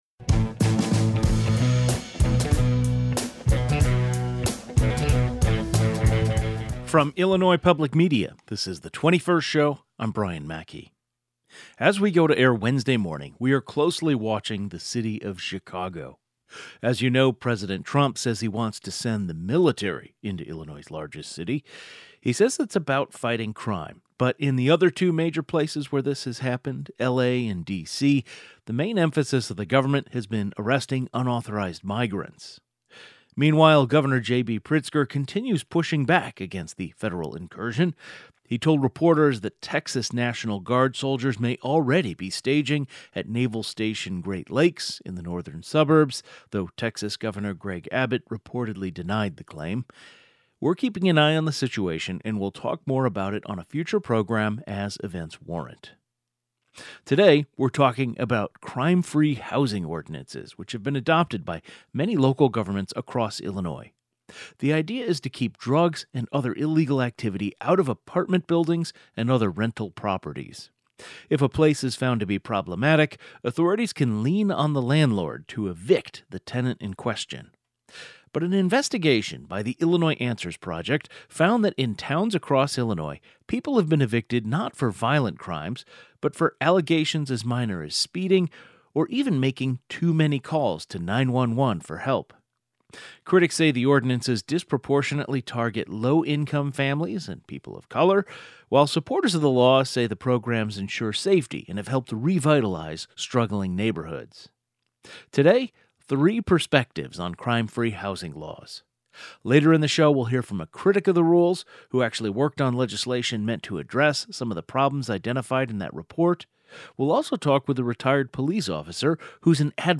Today's show included a rebroadcast of the following "best of" segment, first aired July 22, 2025: Do ‘Crime-Free Housing’ ordinances in Illinois target vulnerable tenants or simply protect neighborhoods?